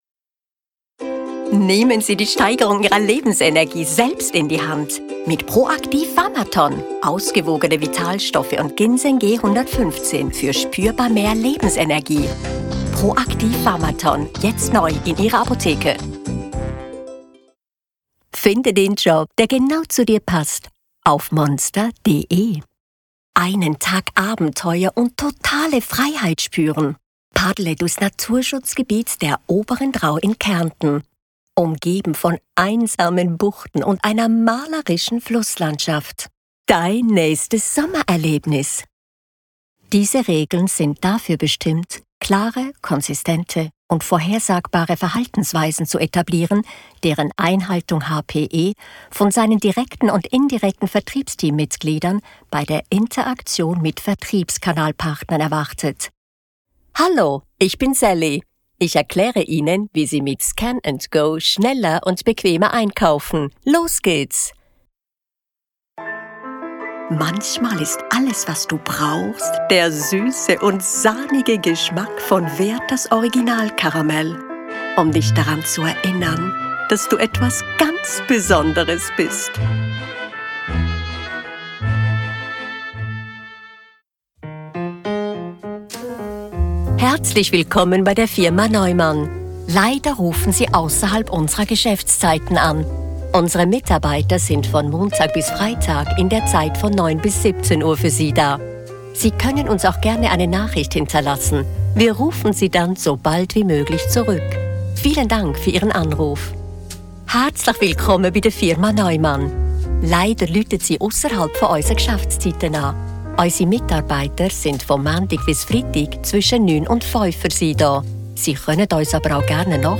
I do the recordings in my home studio and would love to work with you.
Middle Aged